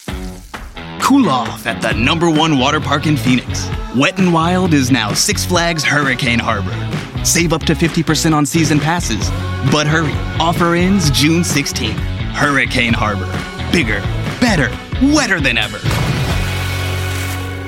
Six Flags Hurricane Harbour - Commercial - Intense